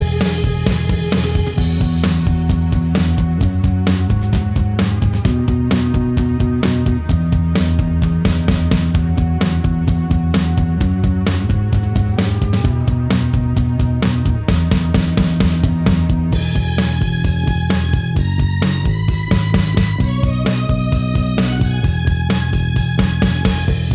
No Guitars Pop (2010s) 3:40 Buy £1.50